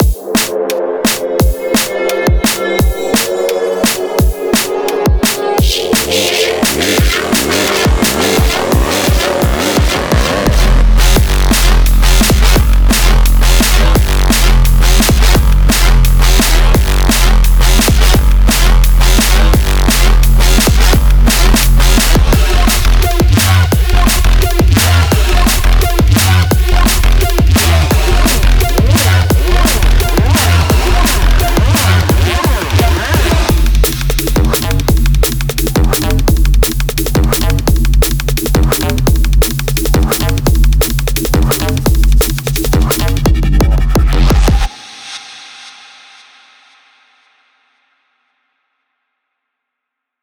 通过音频单发或仅简单但功能强大的现代鼓循环来发挥创意，为您的凹槽赋予新的尺寸。
从鼓到贝司，fx的arp和打击垫，您都可以找到所需的一切，以优质的灵感来完成音轨并节省大量时间。